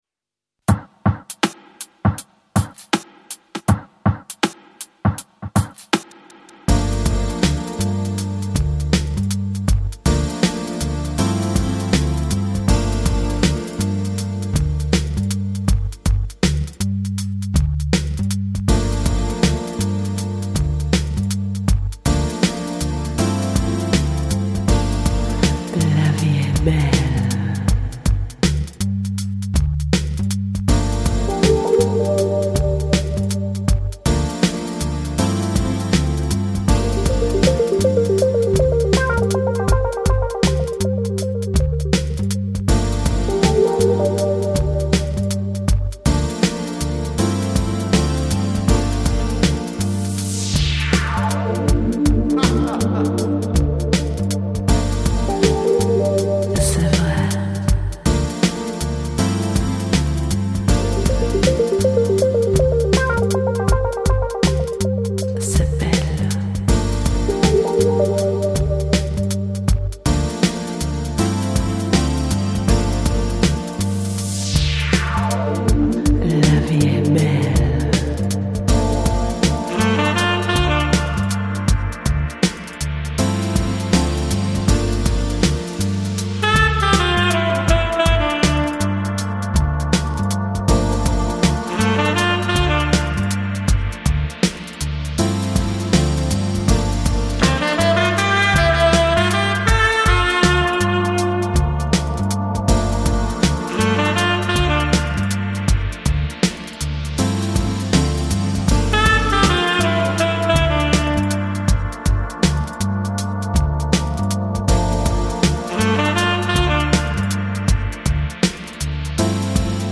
Electronic, Lounge, Chill Out, Downtempo